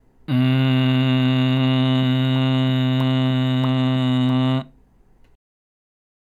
※見本のグーの声(口パクパク編)
「んー」と鼻から声を出しながらの口の開閉をやってもらいます。